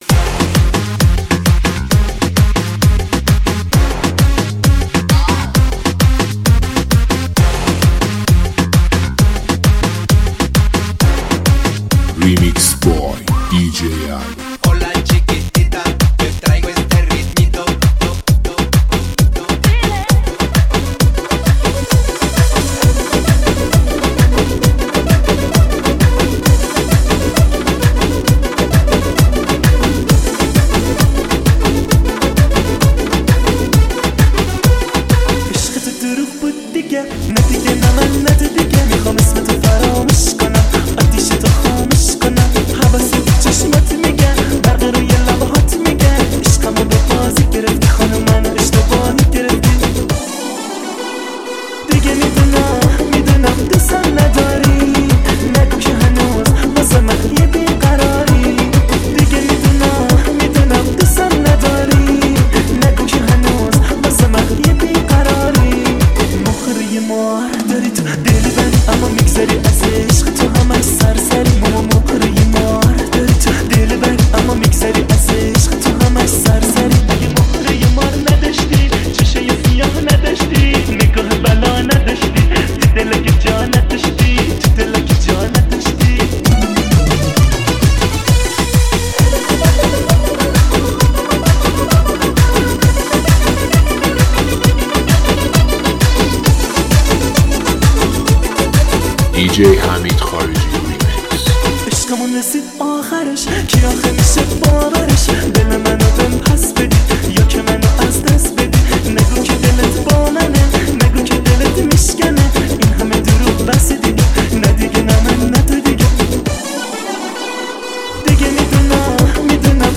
+ ریمیکس موزیک اضافه شد